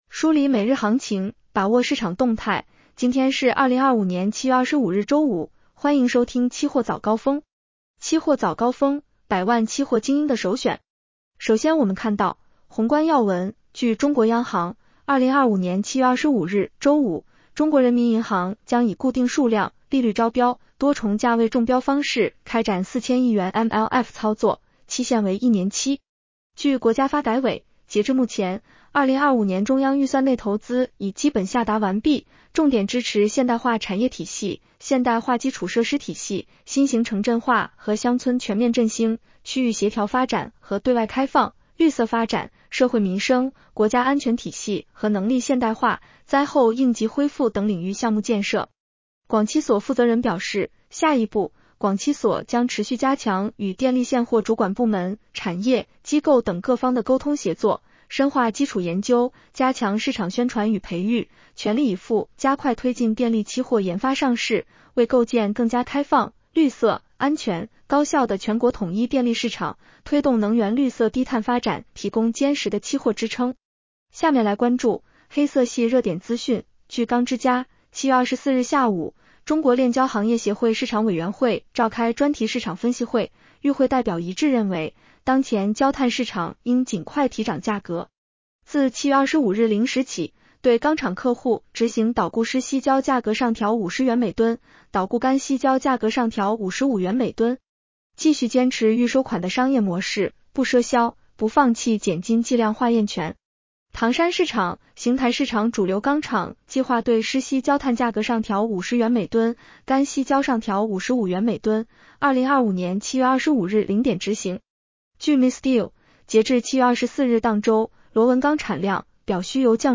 期货早高峰-音频版 女声普通话版 下载mp3 宏观要闻 1.